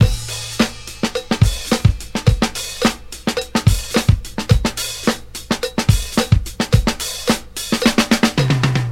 • 108 Bpm Drum Groove A Key.wav
Free drum loop sample - kick tuned to the A note. Loudest frequency: 1023Hz
108-bpm-drum-groove-a-key-rTU.wav